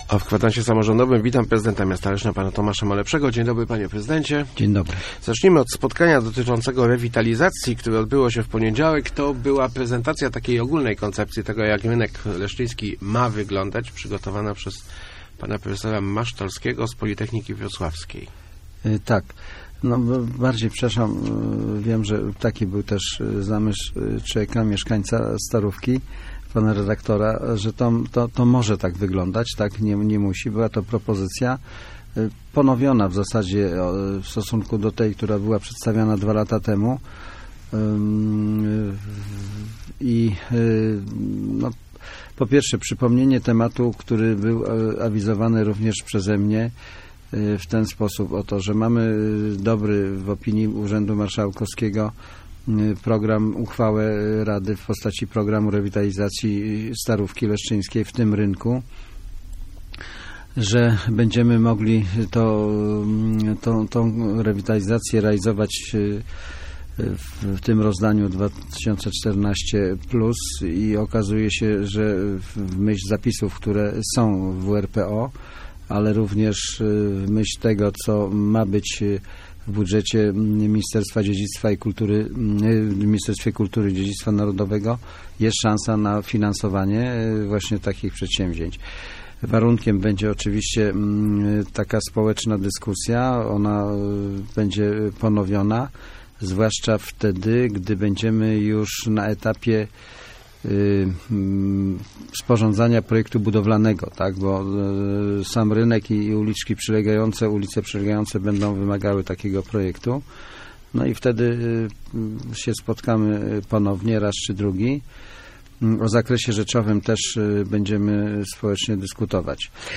Gościem Kwadransa był prezydent Tomasz Malepszy.